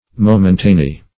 Search Result for " momentany" : The Collaborative International Dictionary of English v.0.48: Momentaneous \Mo`men*ta"ne*ous\, Momentany \Mo"men*ta*ny\, a. [L. momentaneus: cf. F. momentan['e].]
momentany.mp3